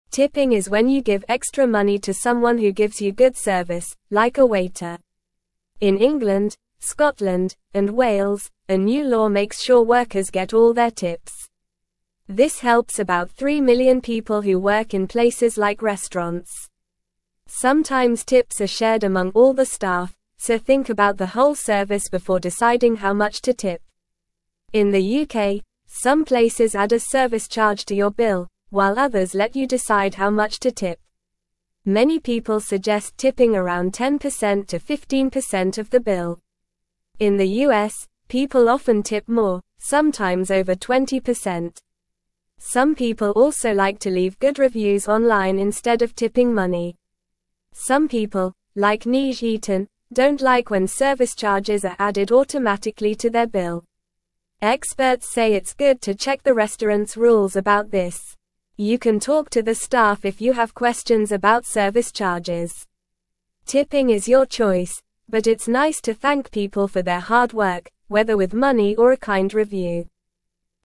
Normal
English-Newsroom-Lower-Intermediate-NORMAL-Reading-Tips-and-Service-Charges-How-to-Say-Thanks.mp3